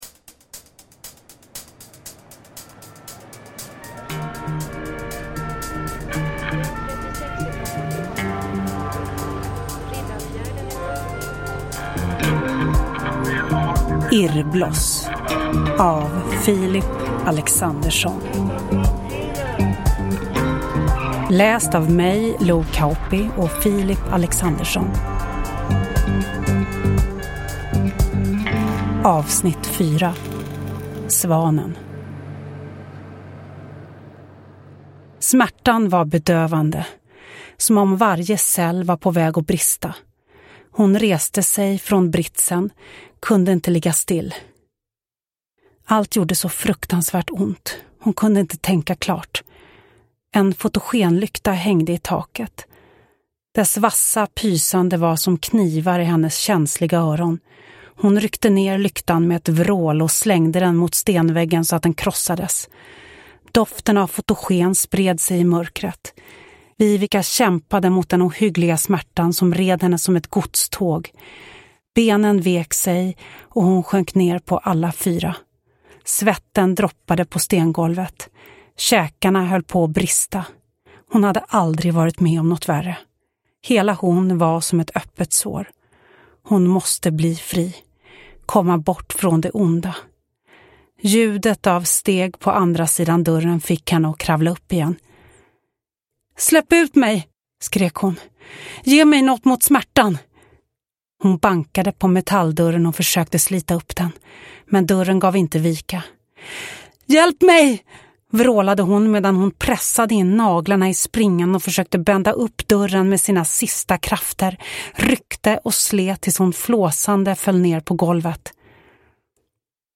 Hidden S1A4 Irrbloss : Svanen – Ljudbok – Laddas ner